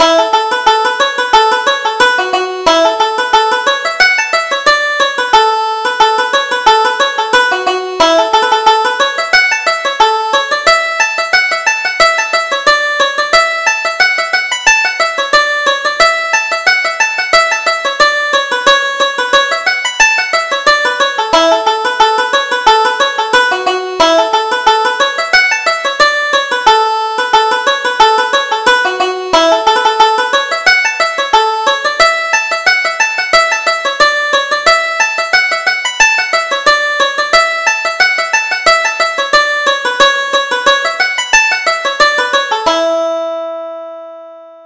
Reel: Shearing the Sheep